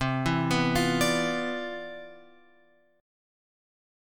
C Major 11th